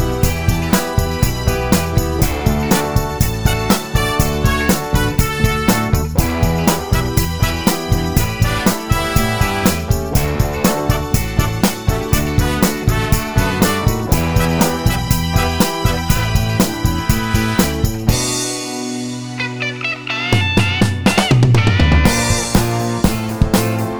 Short Ending No Backing Vocals Pop (1960s) 2:48 Buy £1.50